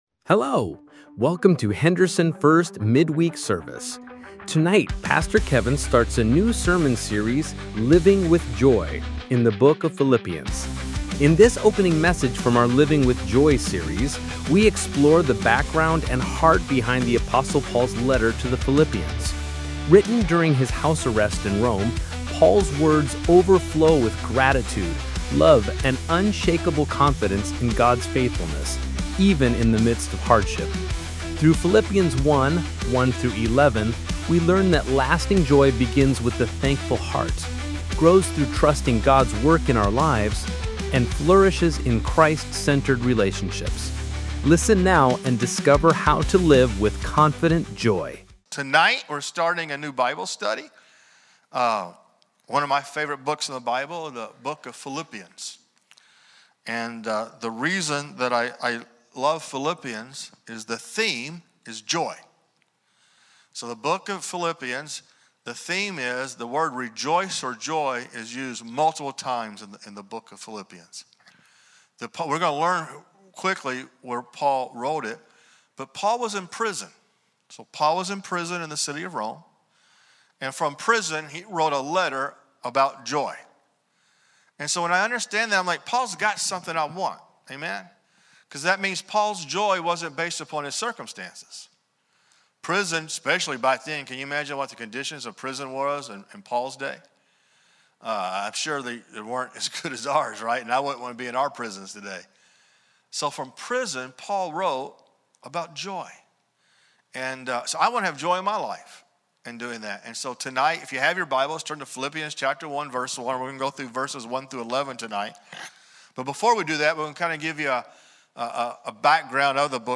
Sermons | First Assembly of God